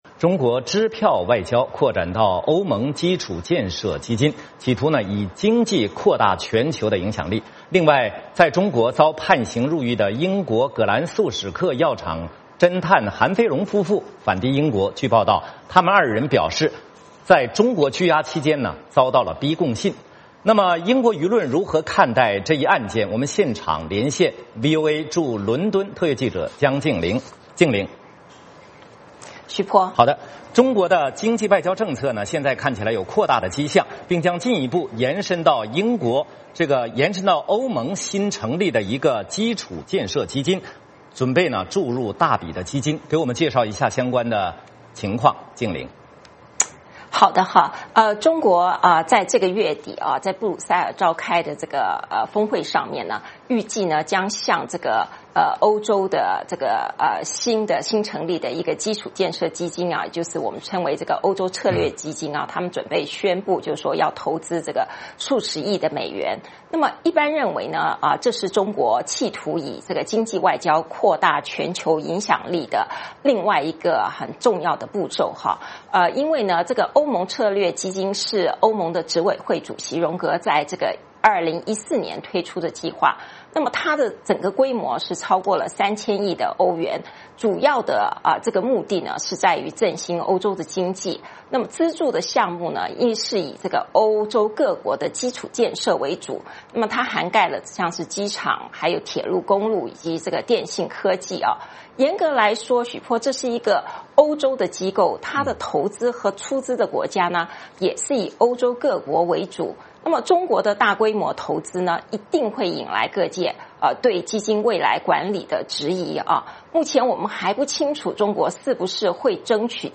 VOA连线：中国对欧洲大举展开支票外交